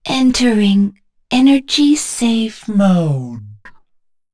voices / heroes / en
Kara-Vox_Dead.wav